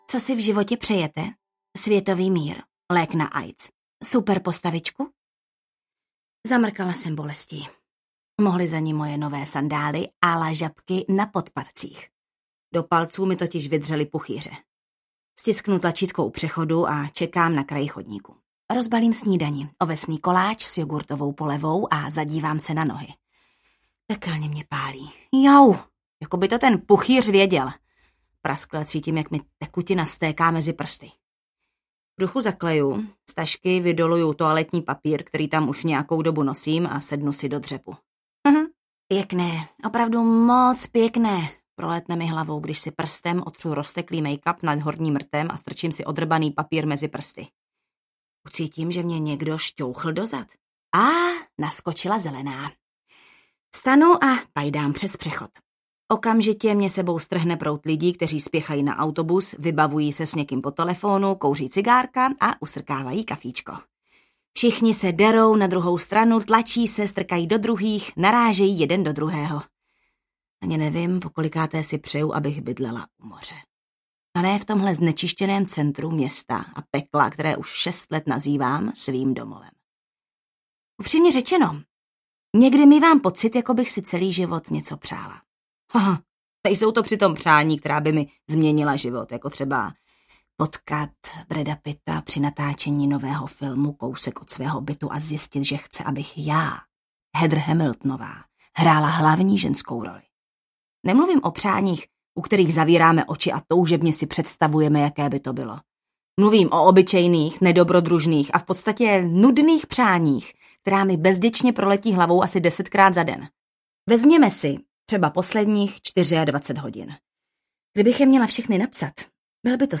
Není přání jako přání audiokniha
Ukázka z knihy